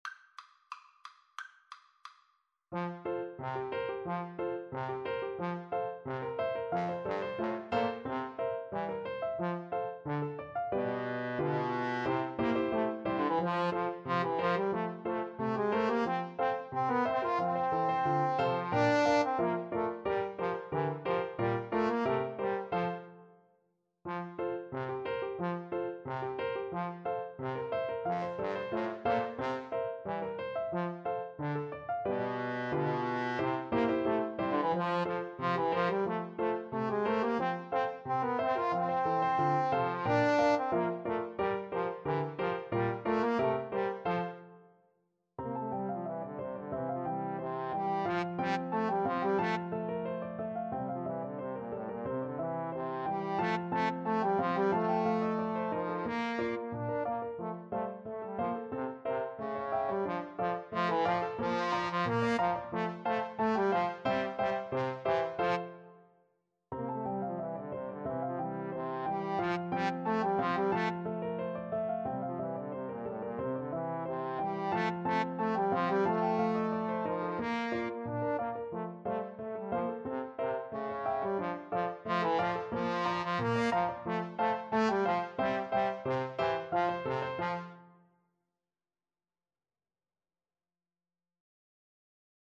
Trombone Duet  (View more Intermediate Trombone Duet Music)
Traditional (View more Traditional Trombone Duet Music)